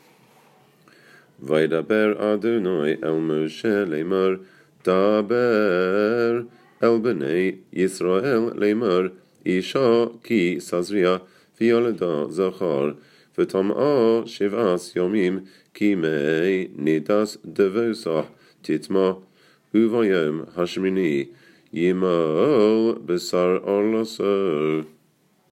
A few pesukim from the beginning of each parashah, in Ashkenazi pronunciation. Pupils can learn from these in order to prepare for their turn to lein in the Shabbos Assembly.